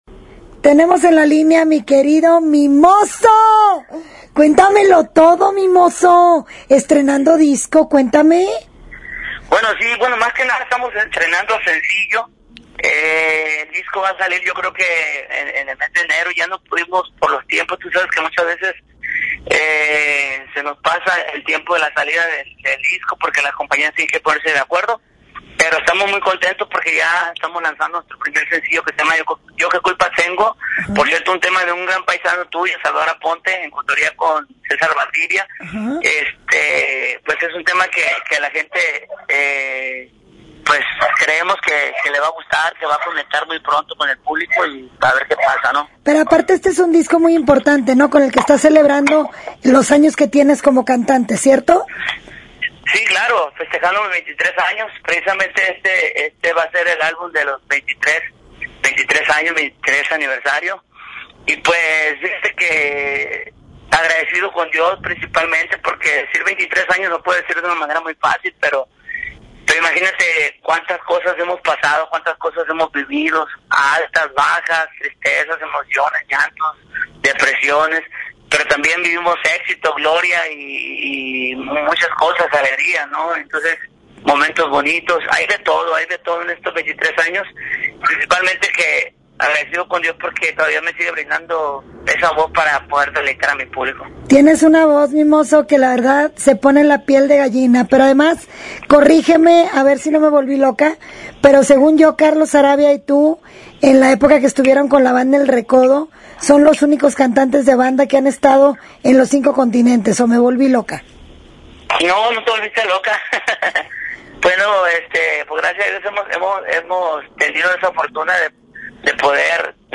Mimoso posee una de las mejores voces en el género de banda y nos dio una entrevista auténtica como es él y nos contó de todo un poco, como por ejemplo que una mujer despechada con la que había intercambiado imágenes cachondonas por las redes sociales las sacó a la luz pensando en que lo perjudicaría, pero lejos de perjudicarlo, a Mimoso le sirvió ¡y mucho!, según él mismo relata.